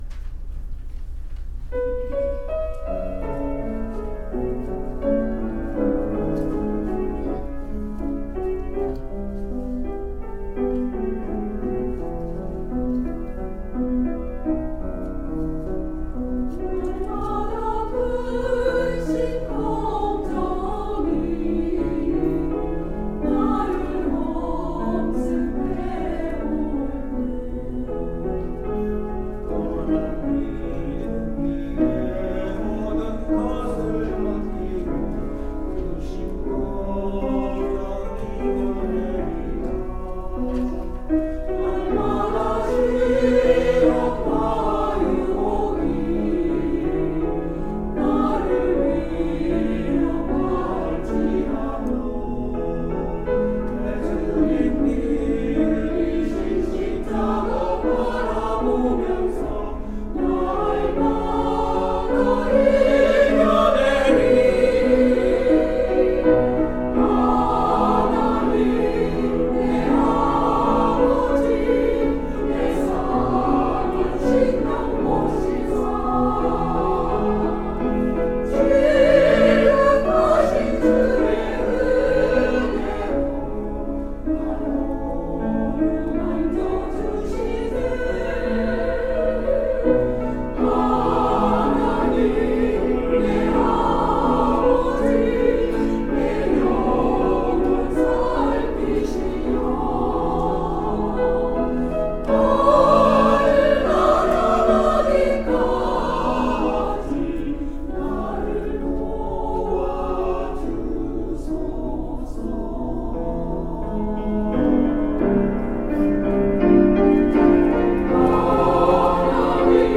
찬양대
[주일 찬양] 날마다